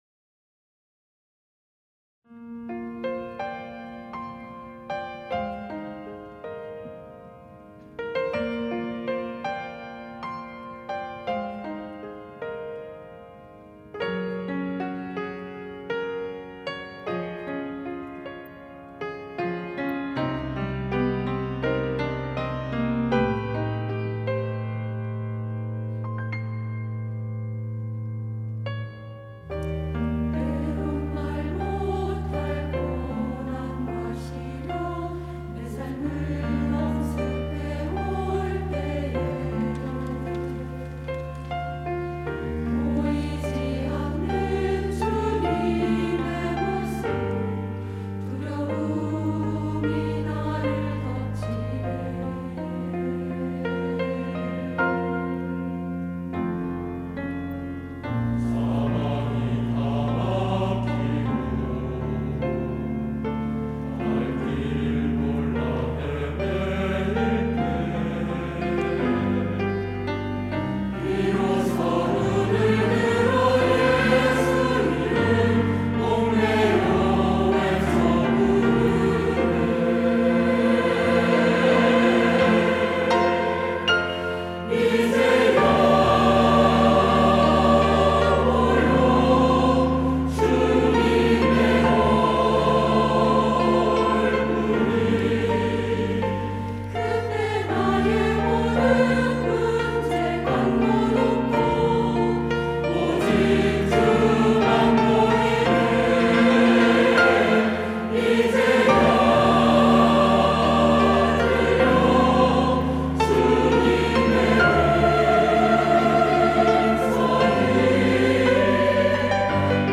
할렐루야(주일2부) - 이제야 보이네
찬양대